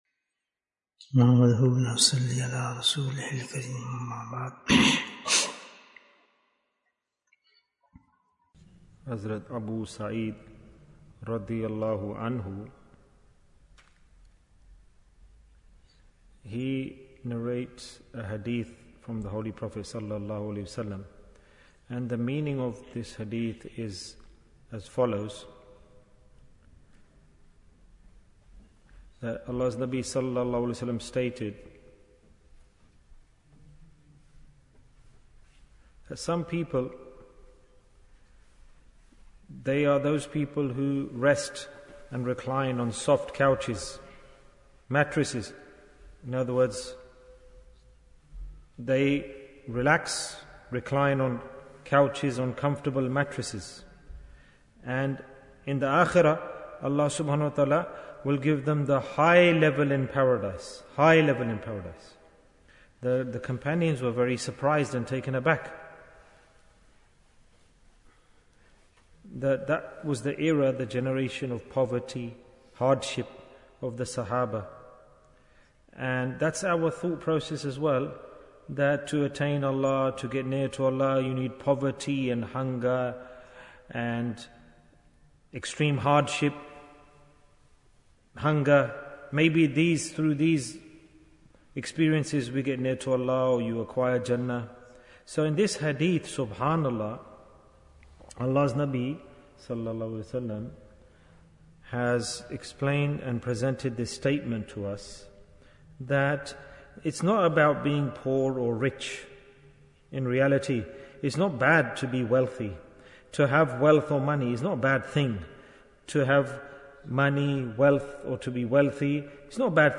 Who is Jannah for? Bayan, 22 minutes19th May, 2023